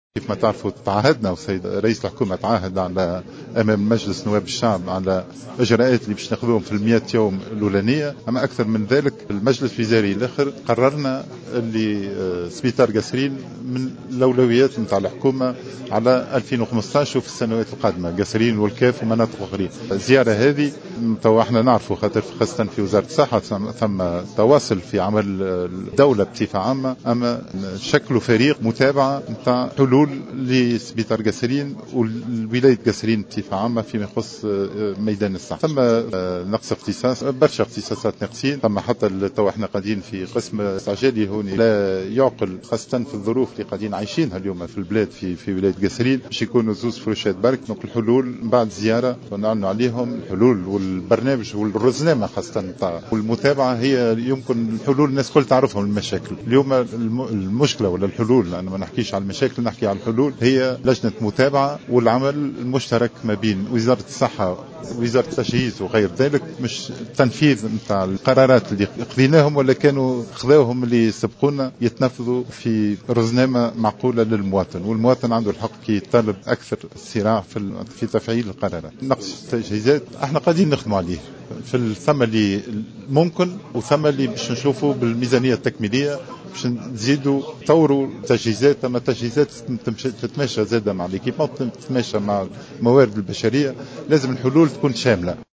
أكد وزير الصحة سعيد العايدي خلال زيارة أداها الى المستشفى الجهوي بالقصرين صباح اليوم الأحد 22 فيفري 2015 أن حكومته قررت خلال المجلس الوزاري الأخير اعطاء الاولوية لمستشفى القصرين والكاف ومناطق أخرى.